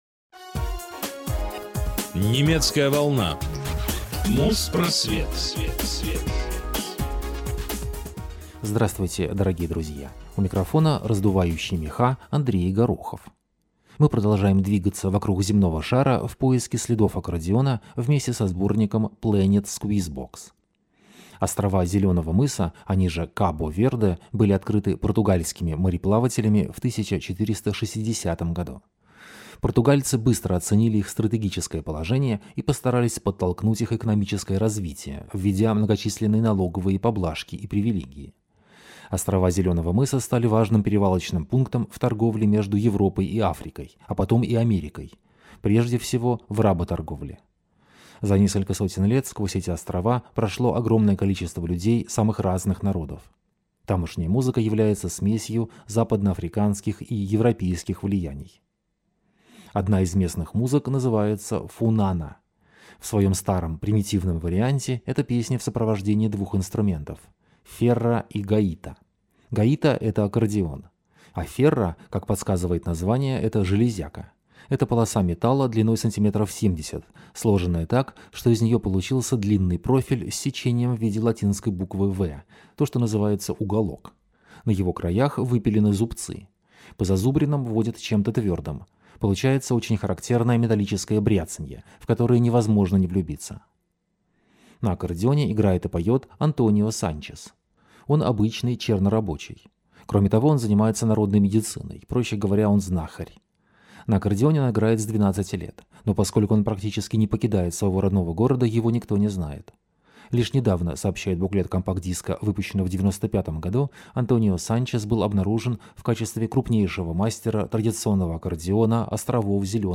Аккордеон. Часть 2 (Африка, Россия, Азия).